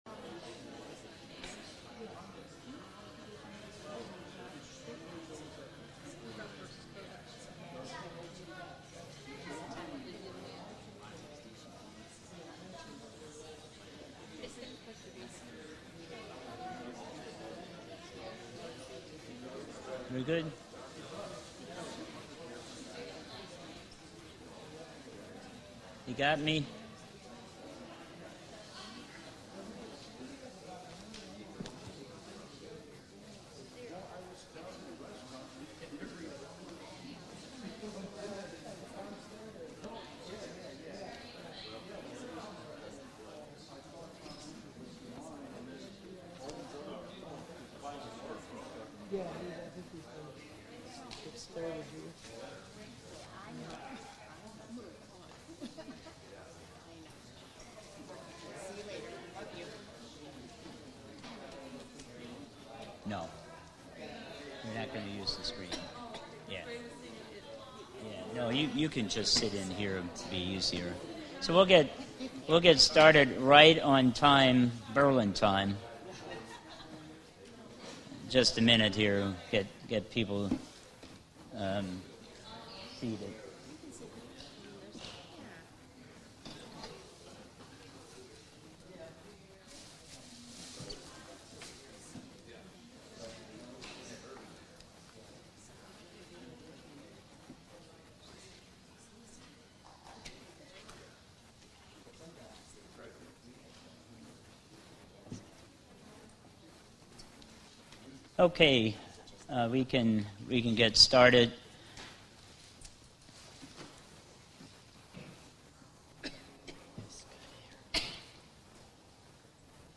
Bible Study: Unpardonable Sin Q&A
Bible Study: Unpardonable Sin Q&A 10/05/2020 - Feast of Tabernacles Berlin, OH 2020